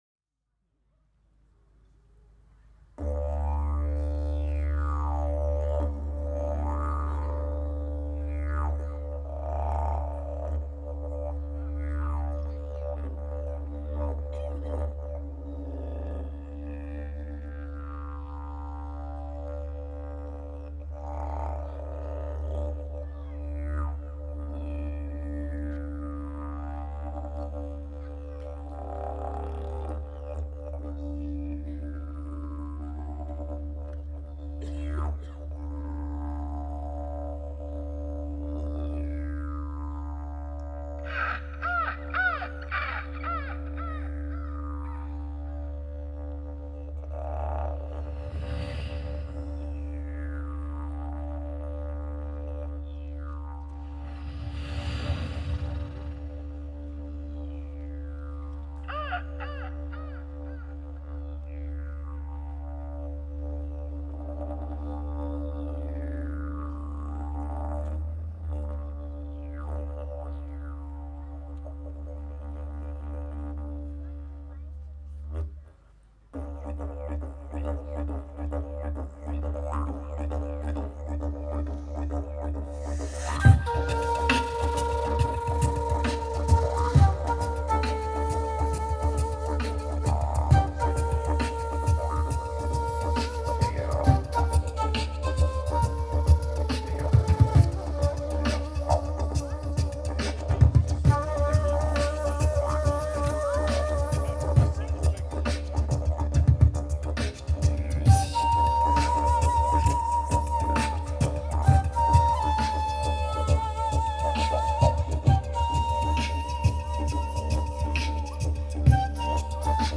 live @ Melbourne Didgeridu & Cultural Festival – 2013
D didj, D shak, beatbox